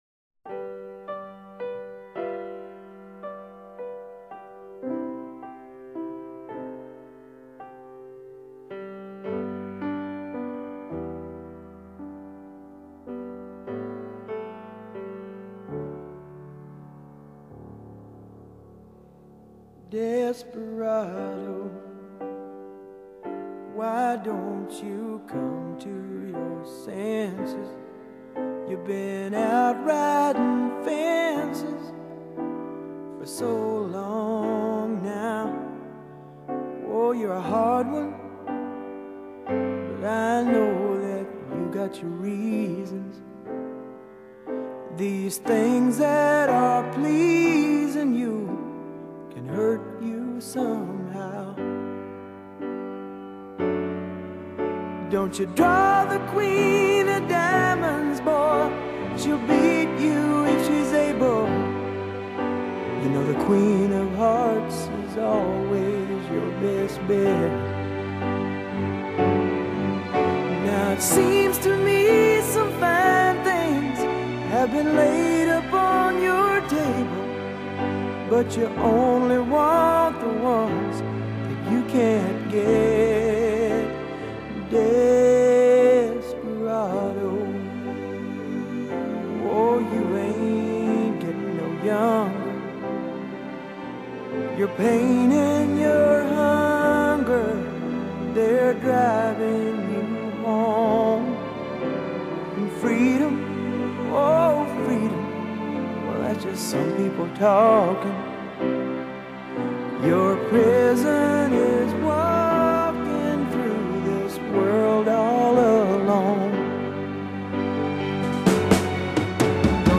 Genre: Classic Rock, Folk Rock, Soft Rock